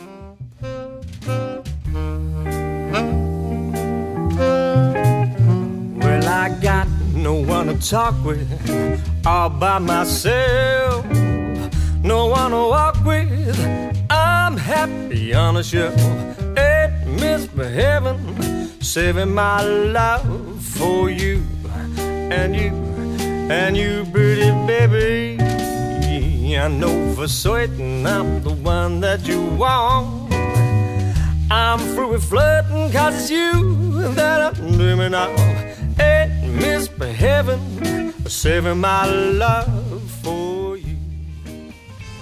• Classic swing jazz and jive band
• Five-piece instrumental line-up
• Guitar, bass, drums, trumpet, sax, vocals